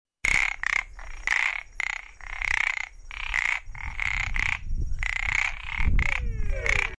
LA PLATA PARADOX FROG Pseudis platensis
Song Recorded Cuenca Upper Yacaré Sur, Departamento Presidente Hayes
Pseudis platensis cuenca upp yacaresur oct08.mp3